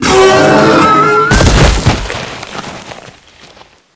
monsterdie.ogg